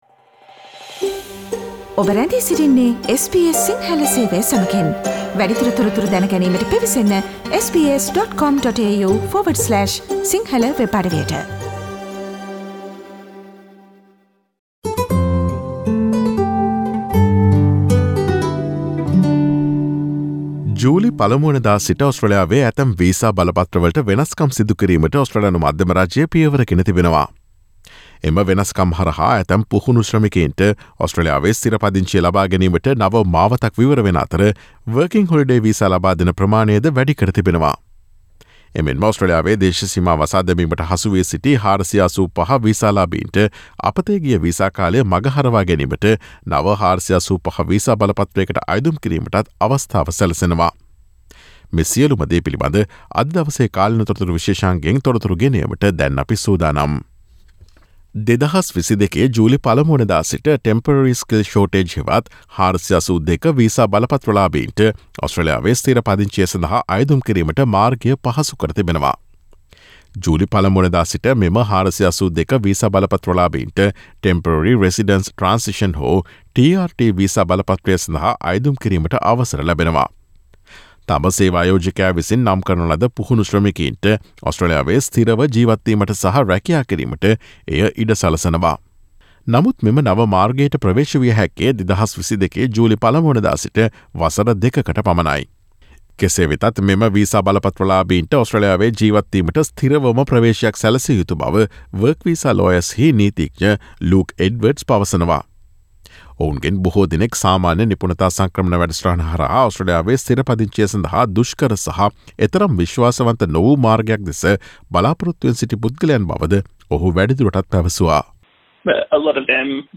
ඔස්ට්‍රේලියාවේ ඇතැම් වීසා ඛාණ්ඩවලට ජූලි 1 වනදා සිට සිදුකෙරෙන සංශෝධන සහ ලබාදෙන සහන පිළිබඳ තොරතුරු රැගත් ජුනි 30 වන දා බ්‍රහස්පතින්දා ප්‍රචාරය වූ SBS සිංහල සේවයේ කාලීන තොරතුරු විශේෂාංගයට සවන්දෙන්න.